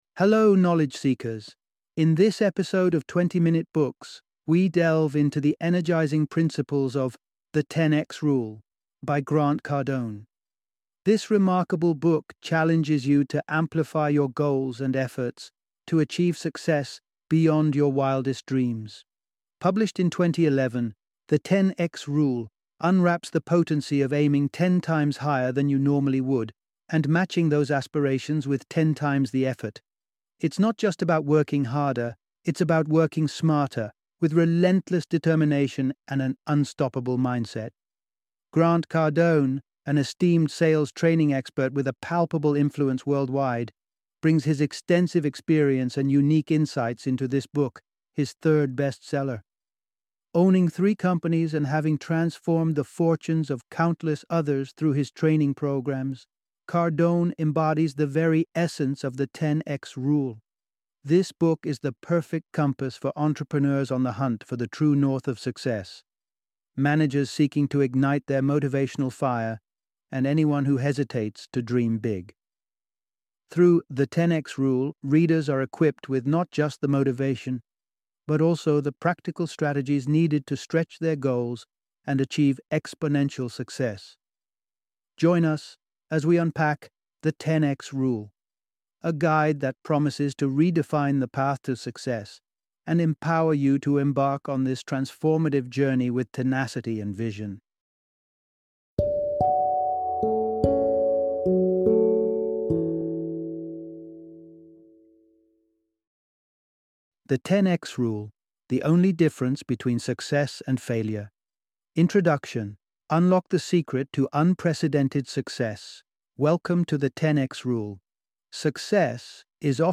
The 10X Rule - Audiobook Summary